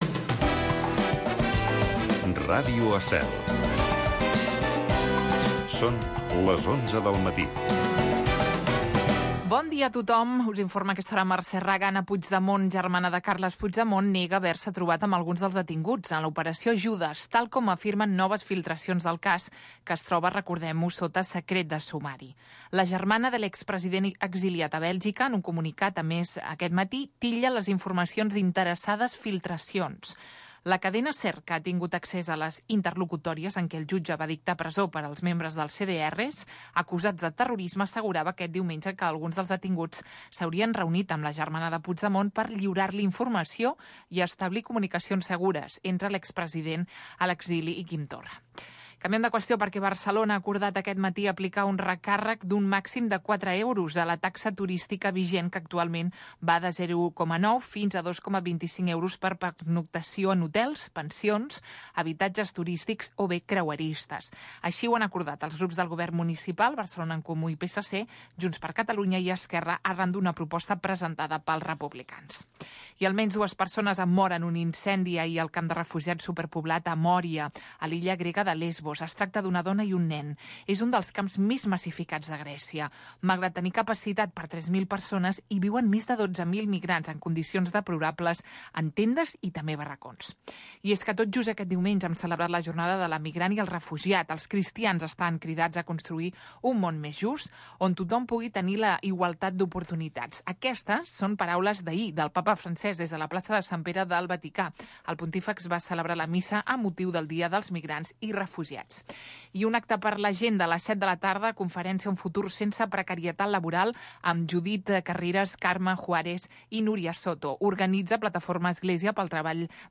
taxa turística de Barcelona, camp de refugiats de Moria (Grècia). Indicatiu dels 25 anys de l'emissora, publicitat, indicatiu.
Gènere radiofònic Entreteniment